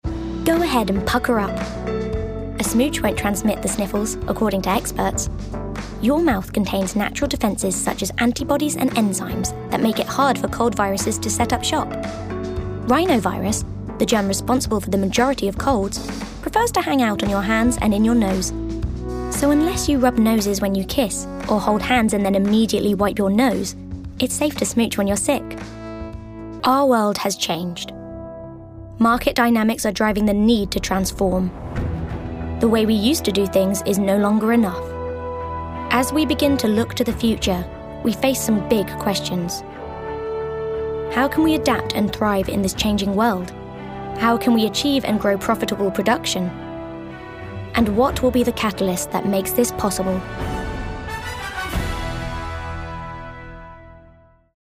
Corporate
Standard English/RP, London/Cockney, American, Yorkshire, Irish
Actors/Actresses, Corporate/Informative, Modern/Youthful/Contemporary, Natural/Fresh, Smooth/Soft-Sell, Quirky/Interesting/Unique, Character/Animation, Upbeat/Energy